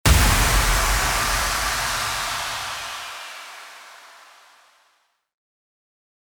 FX-1832-IMPACT
FX-1832-IMPACT.mp3